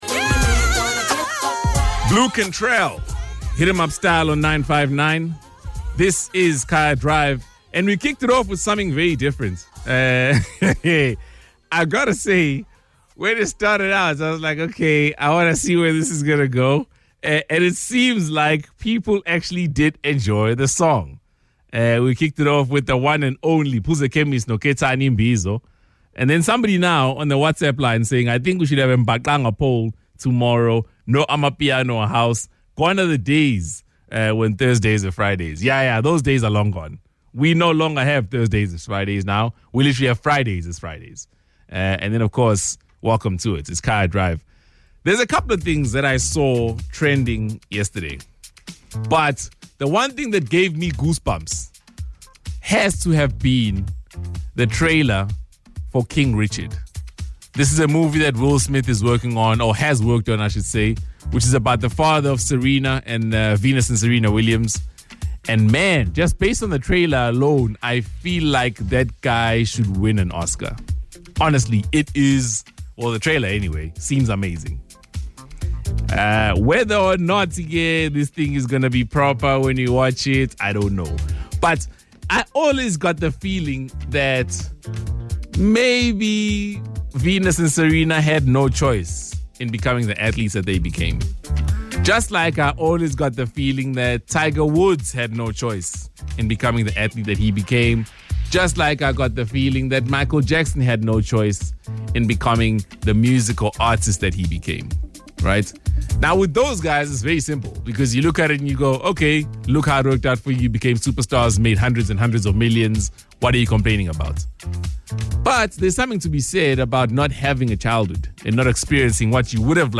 FIRST-HOUR-BANTER-29-JULY-21.mp3